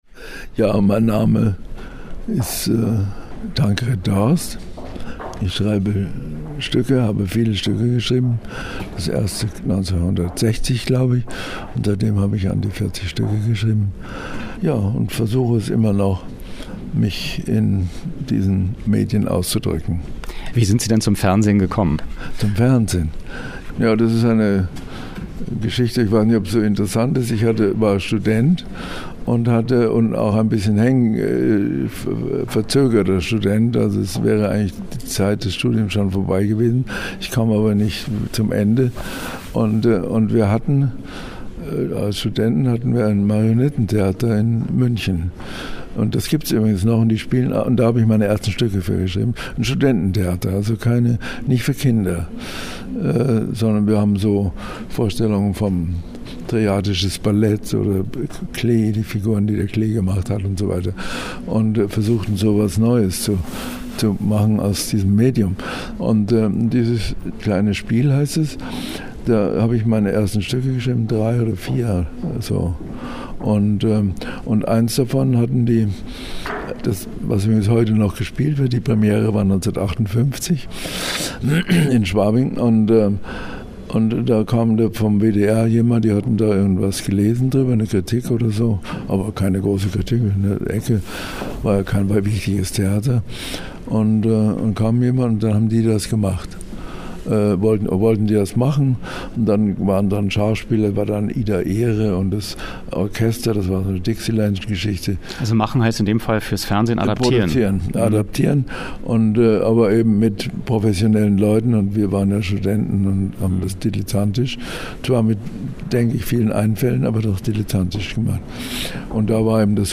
Wer: Tankred Dorst, Schriftsteller und Dramatiker Was: Interview über seine Ansichten vom Fernsehen Wo: Museum für Film und Fernsehen, Potsdamer Straße 2, 10785 Berlin, 4. Etage Wann: 18.05.2011